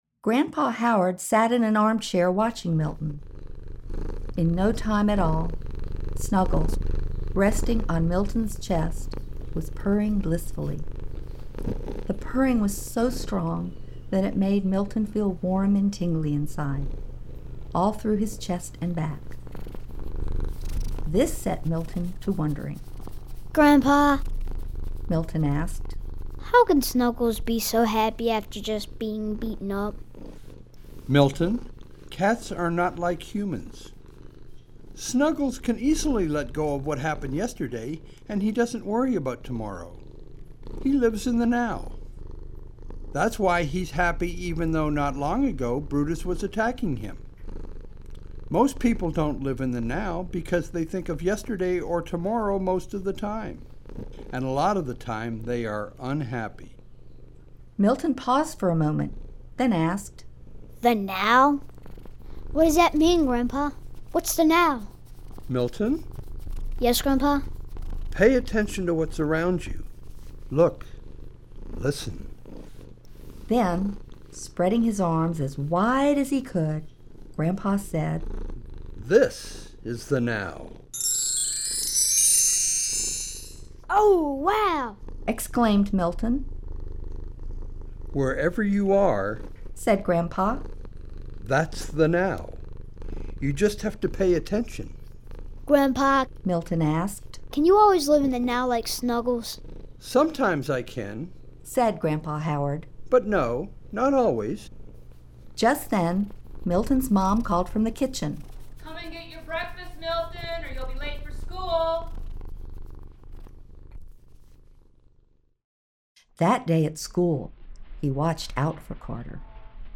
Eckhart Tolle a Robert S. Friedman vytvořili imaginativní pohádku, jež naučí děti způsobu, jak žít v současnosti a pomůže jim čelit obtížím ve škole, na dětském hřišti nebo kdekoli jinde v jejich životě. Pomocí různých hlasů, které představují odlišné postavy, Miltonovo tajemství určitě potěší mladé i starší. Obsahuje frekvence Hemi-Sync®, které napomohou relaxaci těla a soustředění mysli.